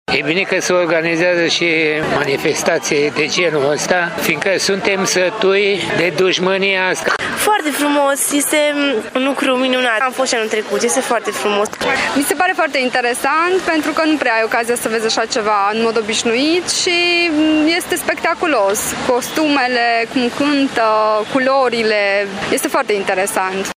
Cea de-a XI-a ediţie a Festivalului Văii Mureşului a avut loc sâmbătă şi duminică la Răstoliţa, pe platoul La Alei.
Vizitatorii au fost încântaţi de frumuseţea festivalului, iar unii dintre ei au participat şi la ediţiile anterioare.